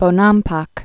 (bō-nämpäk)